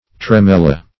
Tremella \Tre*mel"la\, n. [NL., fr. L. tremere, to tremble: cf.